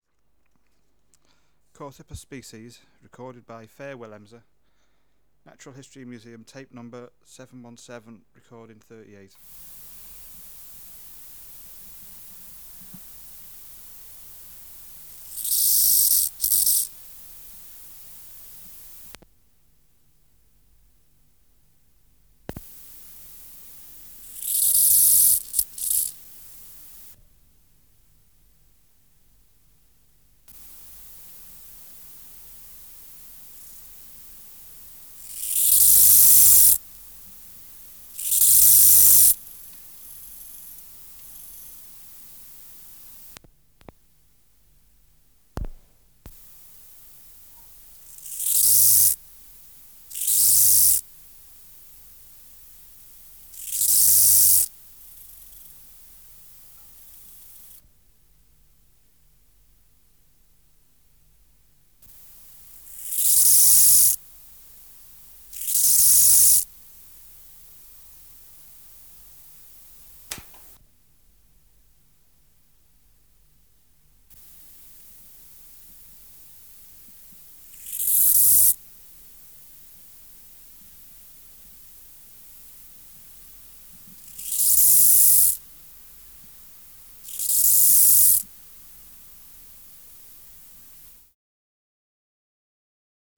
Project: Natural History Museum Sound Archive Species: Chorthippus